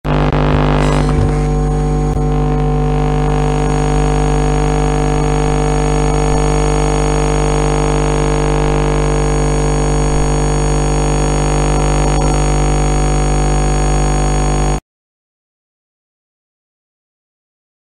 Fnaf 2 Static Bouton sonore